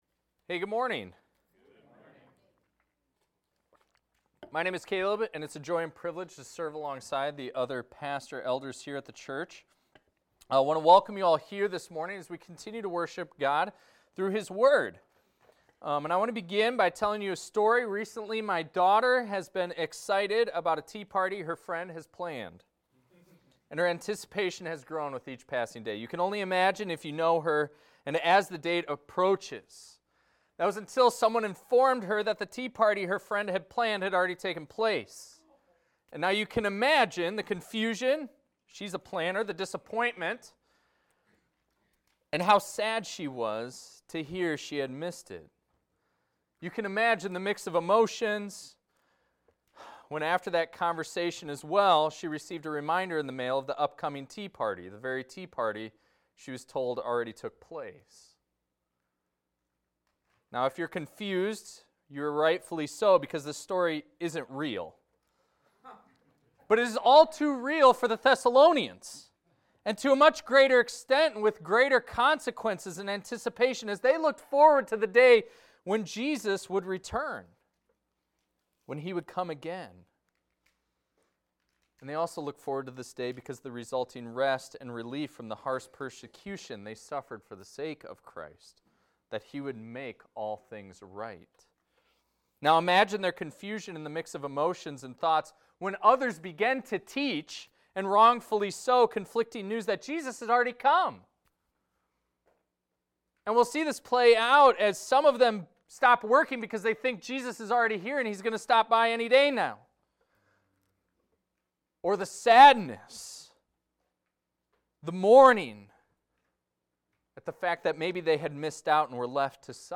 This is a recording of a sermon titled, "Let No One Deceive You."